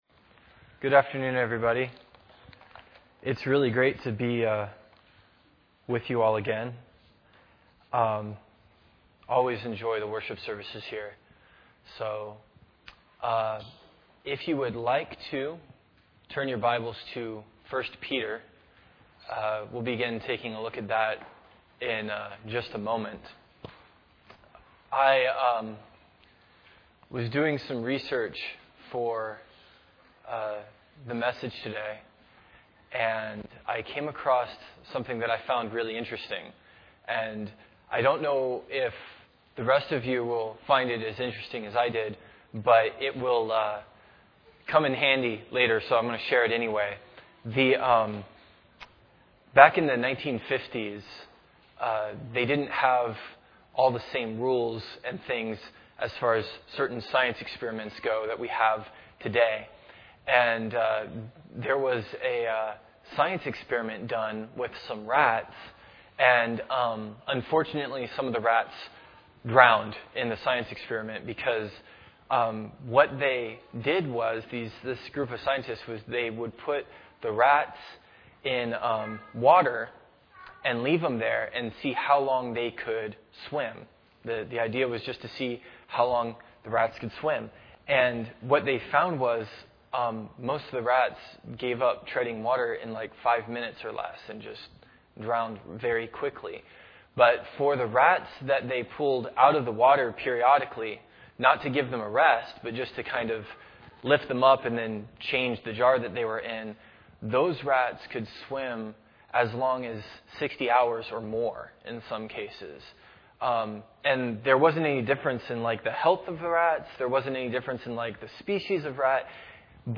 Welcome to the weekly teaching